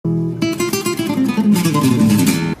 (73.84 KB that one spanish guitar riff.mp3)